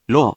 If you press the 「▶」button on the virtual sound player, QUIZBO™ will read the random hiragana to you.
In romaji, 「ろ」 is transliterated as 「ro」which sounds sort of like 「low」